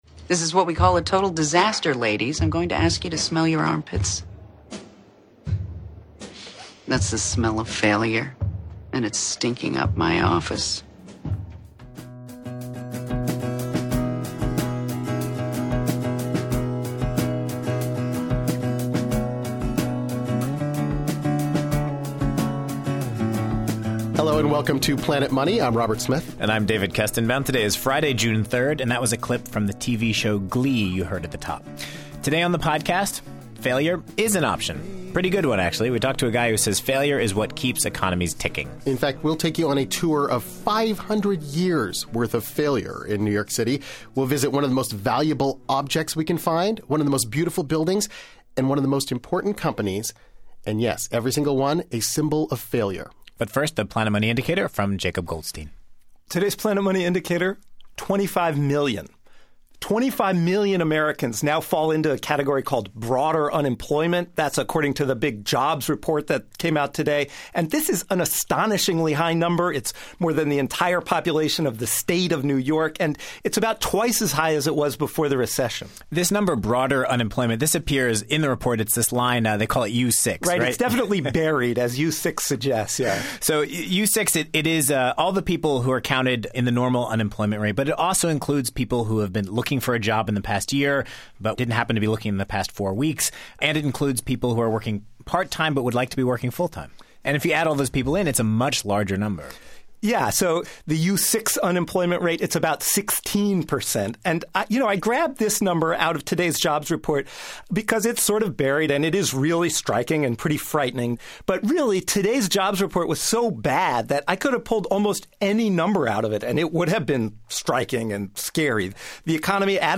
On today's Planet Money, we hit the streets of Manhattan with economist Tim Harford. In his new book, Adapt, Harford argues that success always starts with failure. Harford takes us on a failure tour of New York.